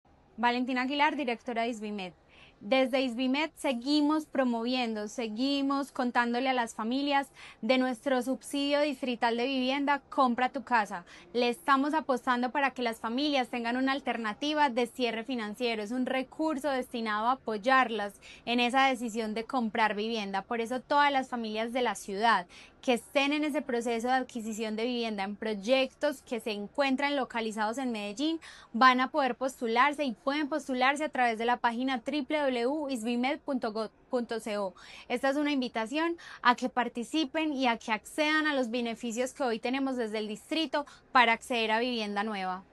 Declaraciones directora del Isvimed, Valentina Aguilar Ramírez En lo que va del año, la Administración Distrital ha entregado subsidios Compra tu Casa por más de $900 millones.
Declaraciones-directora-del-Isvimed-Valentina-Aguilar-Ramirez.mp3